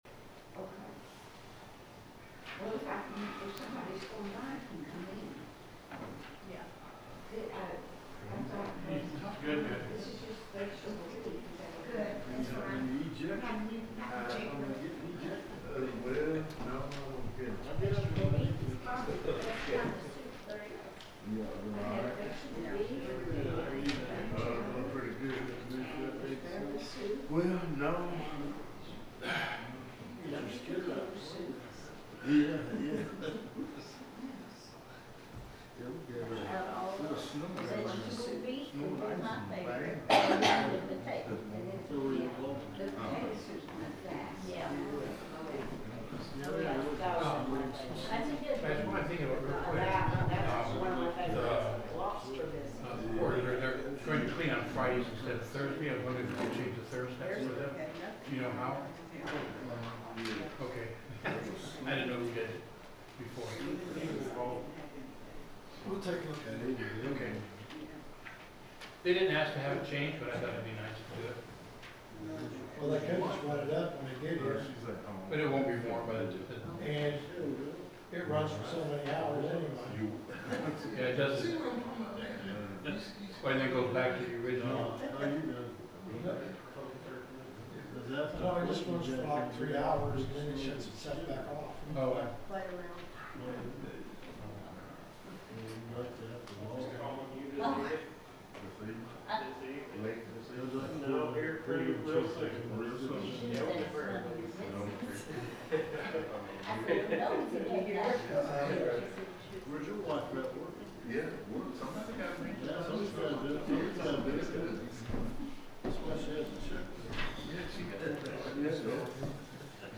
The sermon is from our live stream on 1/21/2026